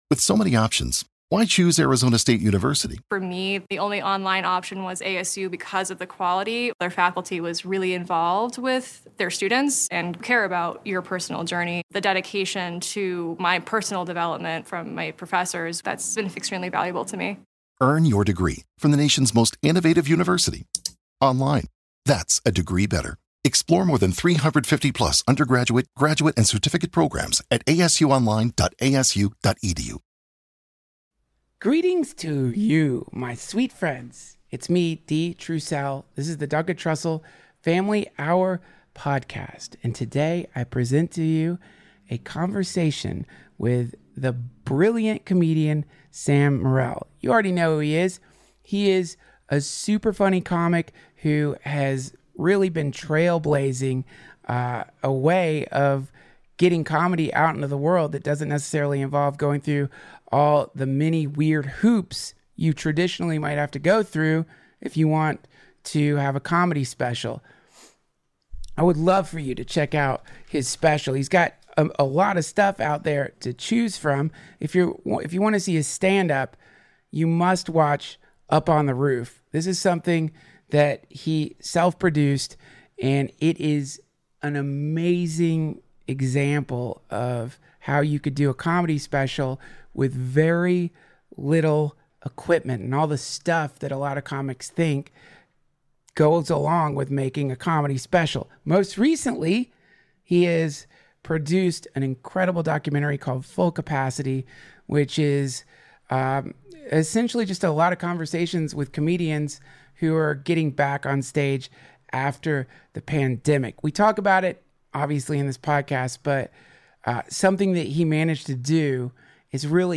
Sam Morril, brilliant comedian, joins the DTFH!